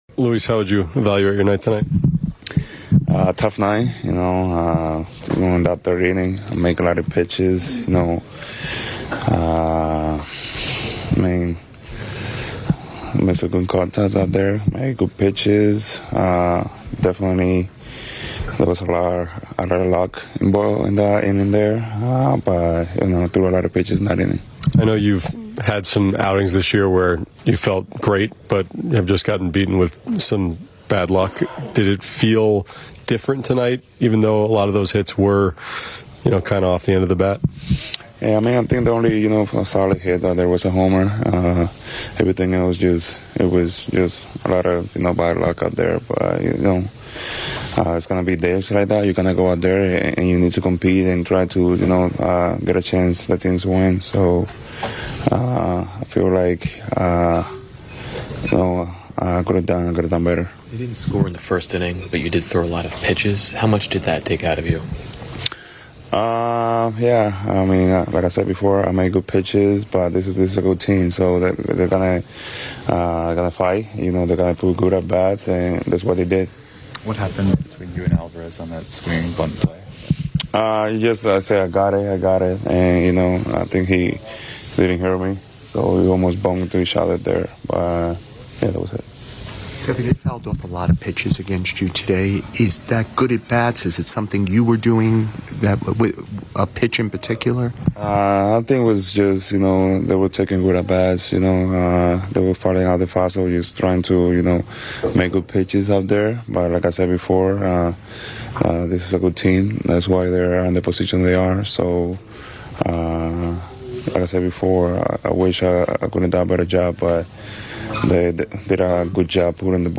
WCBSAM: On-Demand - Postgame: Luis Severino